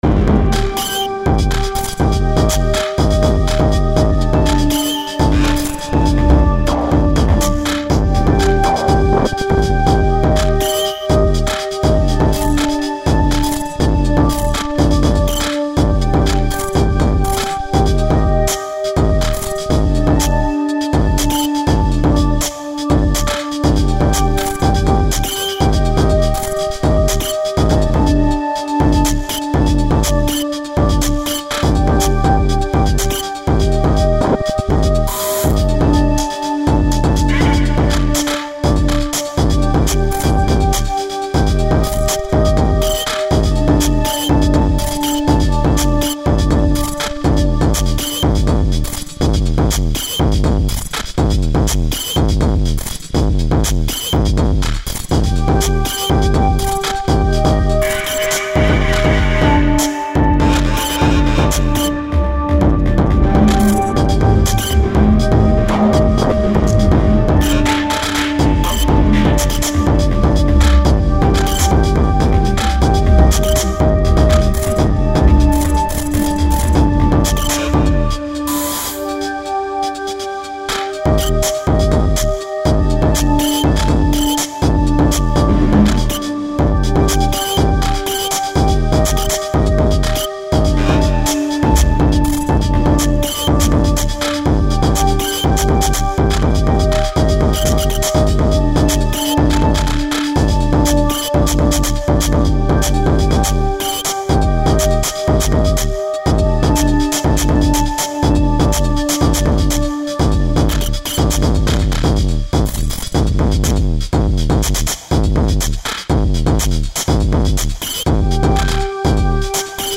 File under: Electronica / IDM
Generated using laptop computer technology
a truly miniature sci-fi soundtrack!